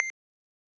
push.wav